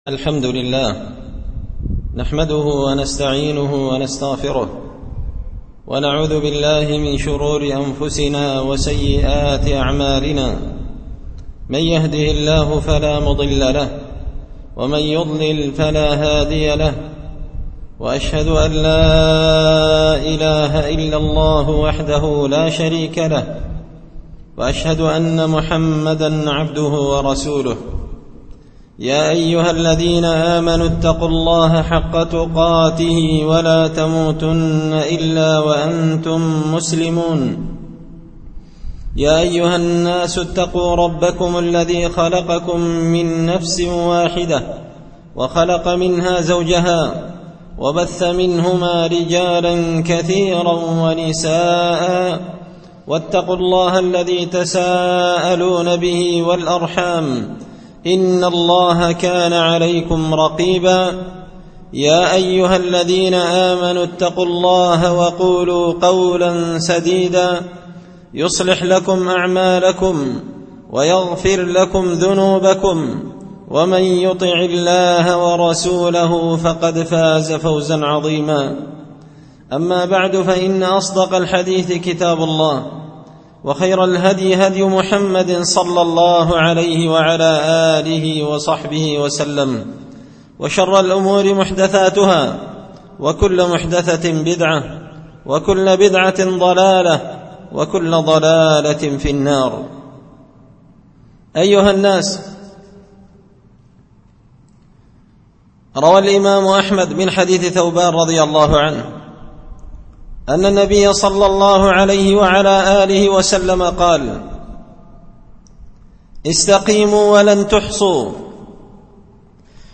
خطبة جمعة بعنوان – الصلاة خير الأعمال
دار الحديث بمسجد الفرقان ـ قشن ـ المهرة ـ اليمن